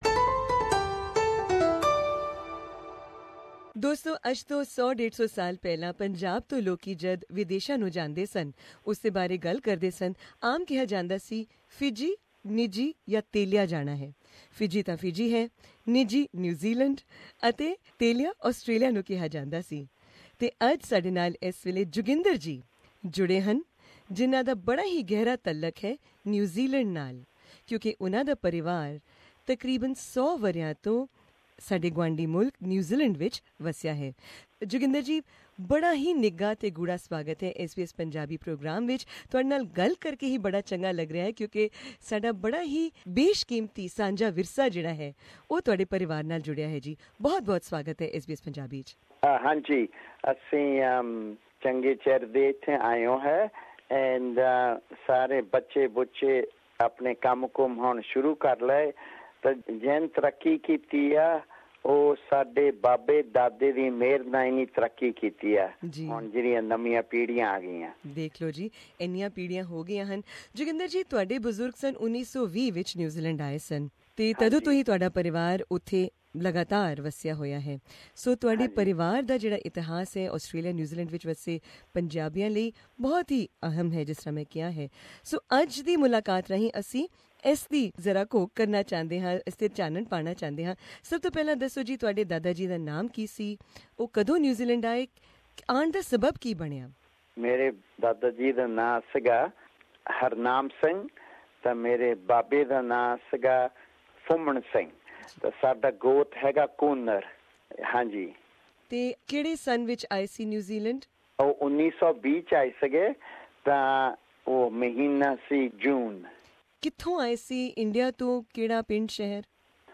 To hear his interview with SBS Punjabi, please click on the link above.